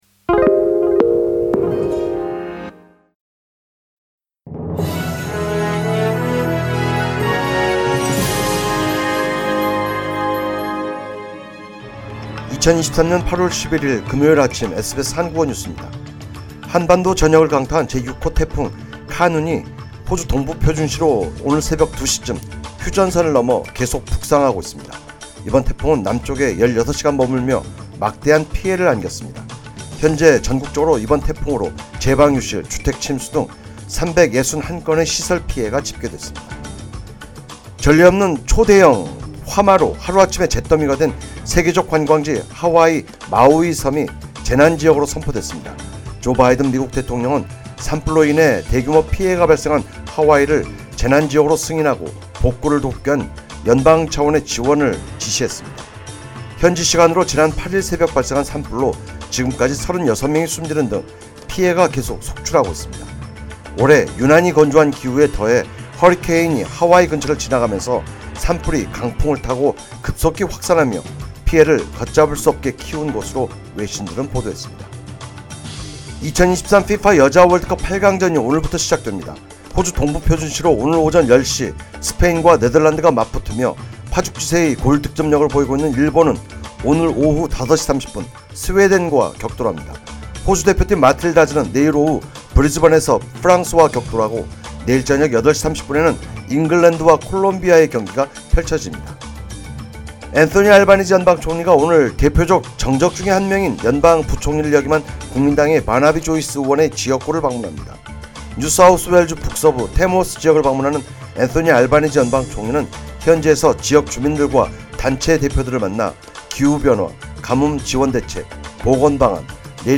2023년 8월 11일 금요일 SBS 한국어 아침 뉴스입니다.